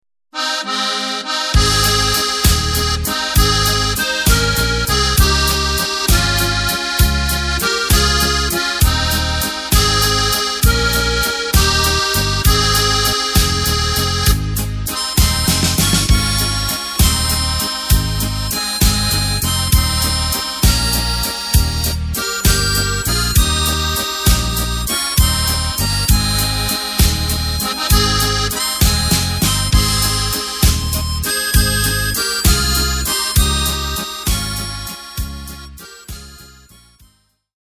Takt:          3/4
Tempo:         198.00
Tonart:            Ab
Walzer Tradition!